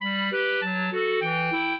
minuet9-7.wav